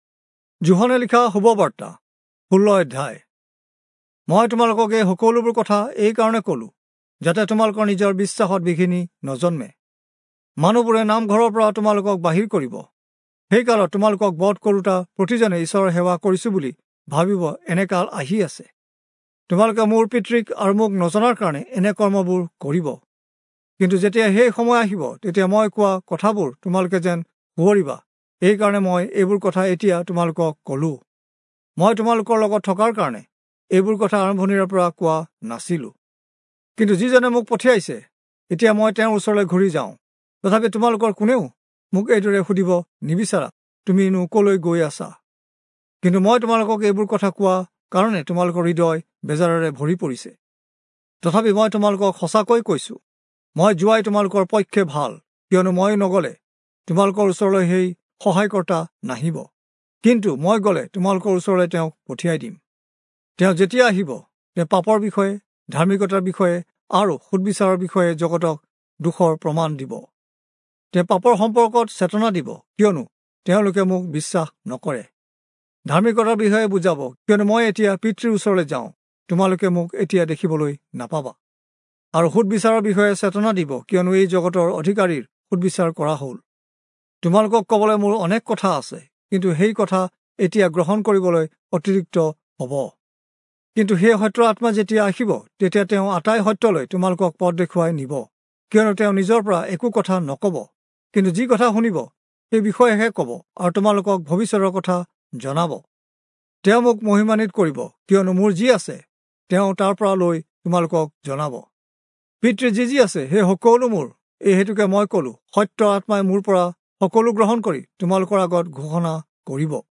Assamese Audio Bible - John 13 in Alep bible version